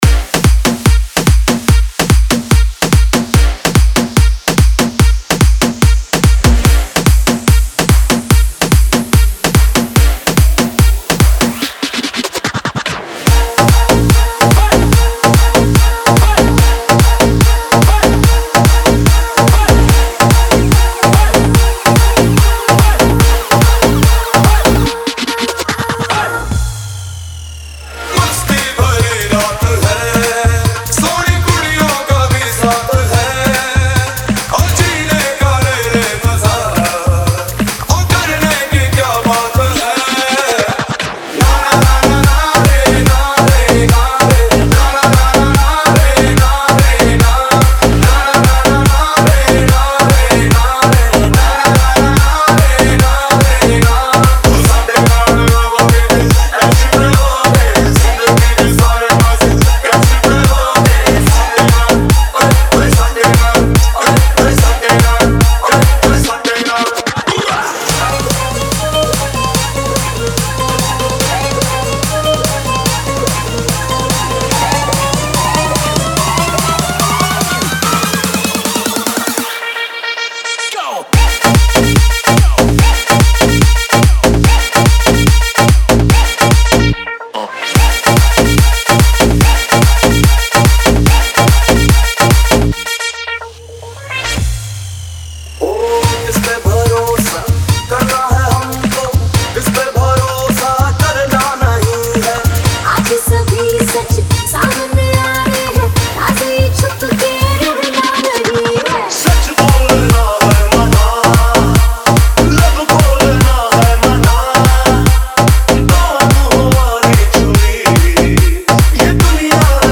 2025 Bollywood Single Remixes Song Name